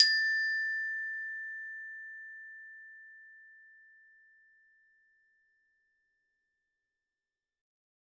Hard_plastic_f_A4.wav